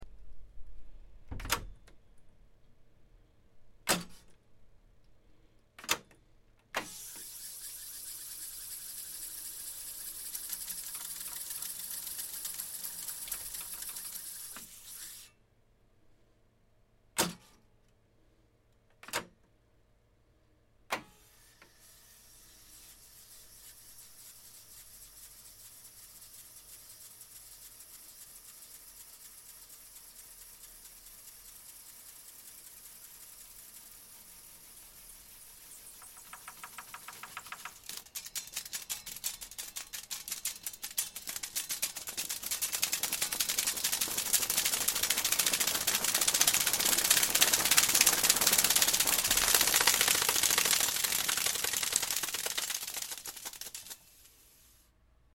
Tascam 32 Transport Mechanism Tape Machine
1980s Tascam 32 Tape machine transport mechanism. Play, stop fast forward and rewind including tape flapping where the machine carried on rewinding!